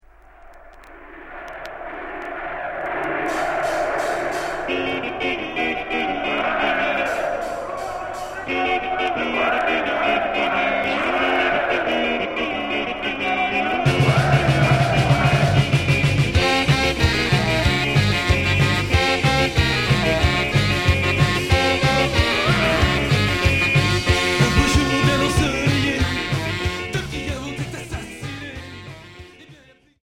Anarcho punk